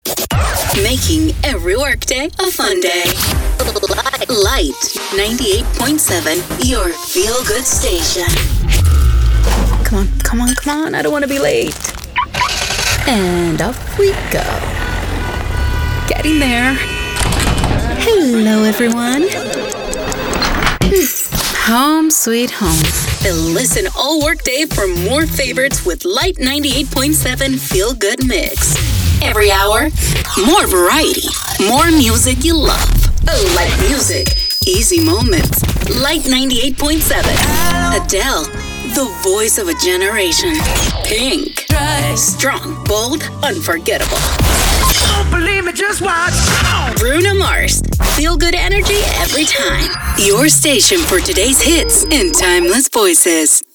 Radio Imaging English Demo
AC Classic Hits Demo
AC-Classic-Hits-Demo.mp3